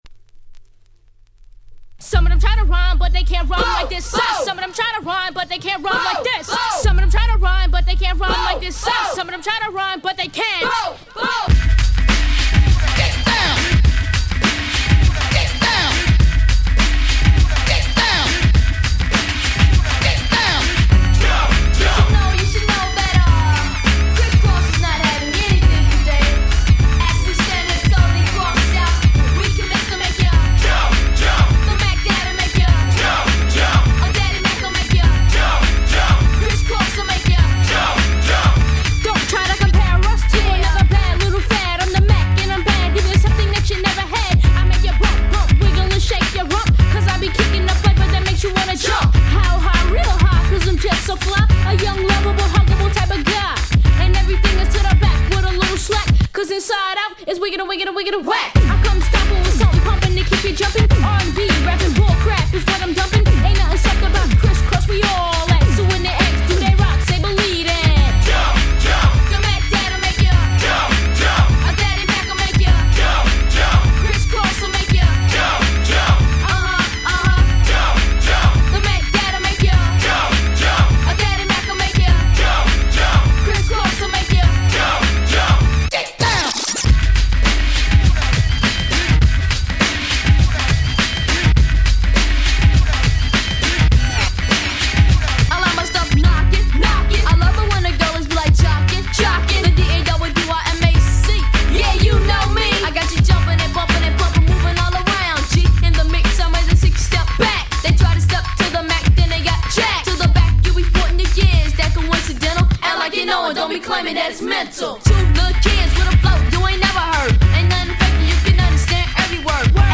92' Super Hit Hip Hop !!
服を後ろ前にクールに着こなしたガキンチョ二人が歌う、完全無血のフロアアンセム！